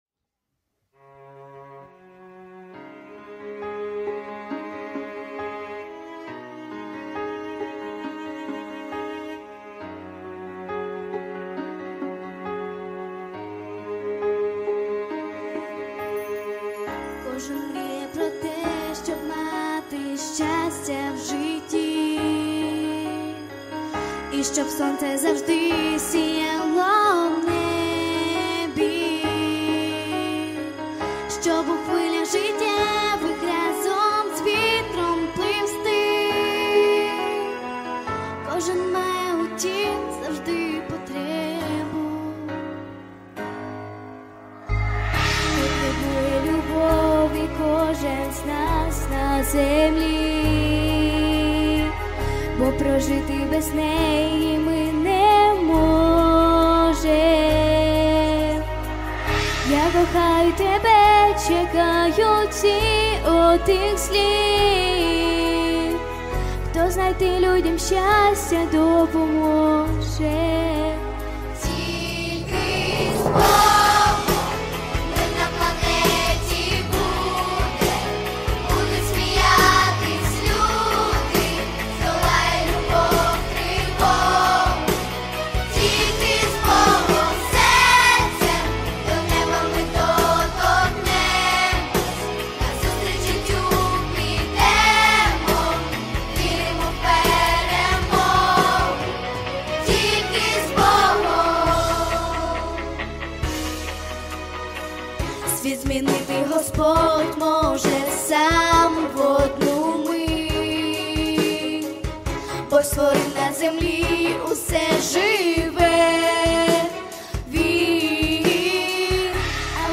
• Жанр: Детские песни
христианские песни